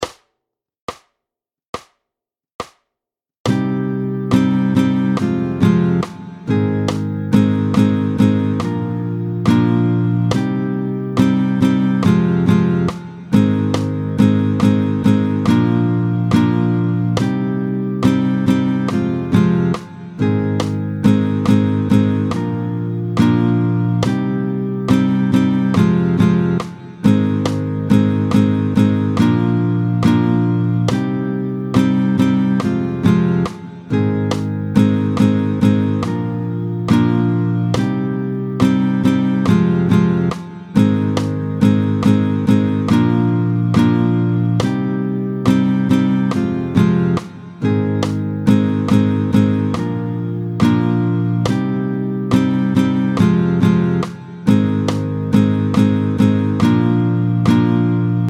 tempo 70